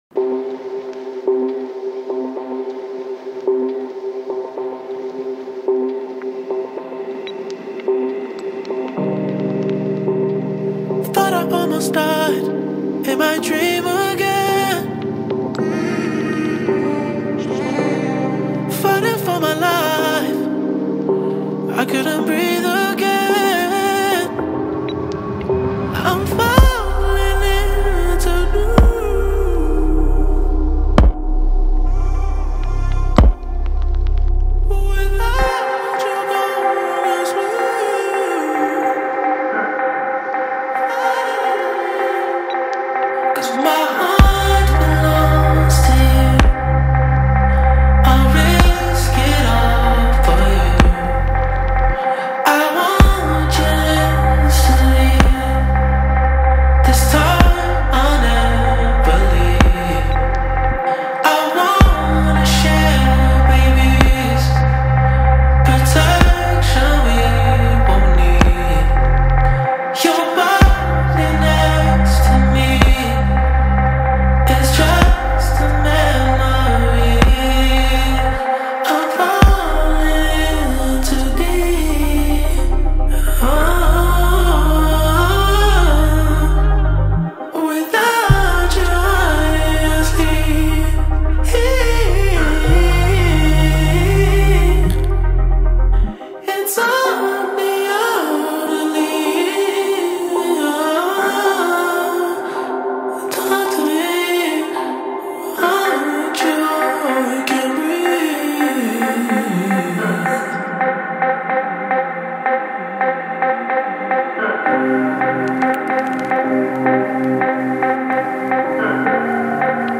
دارک آراندبی